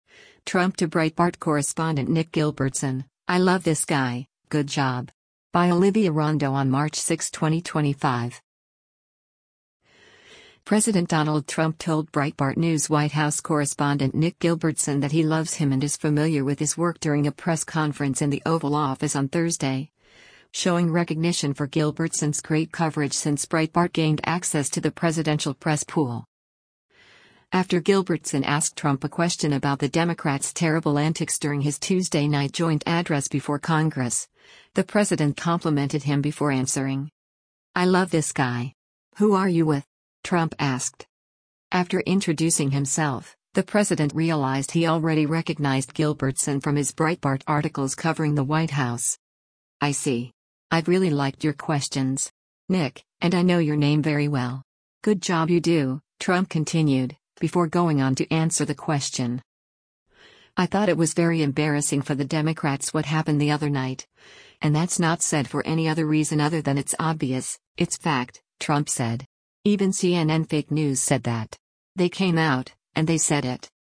during a press conference in the Oval Office on Thursday